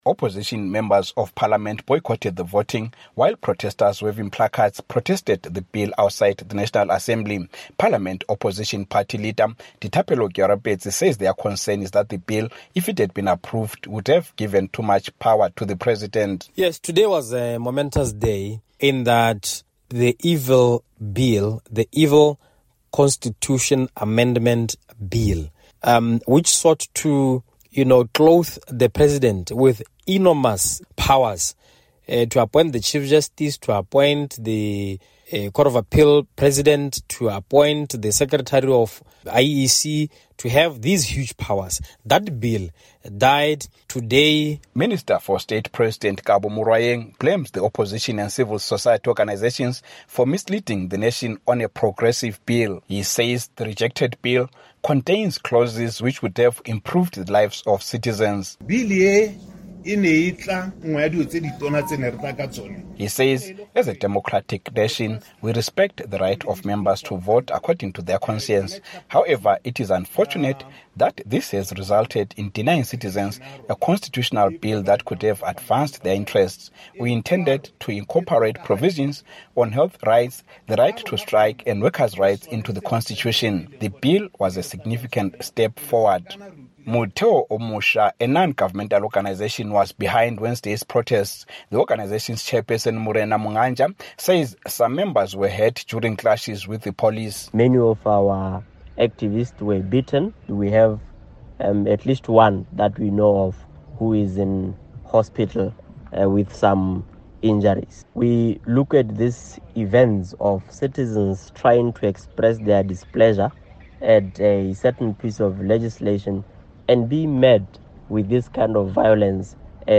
From Gaborone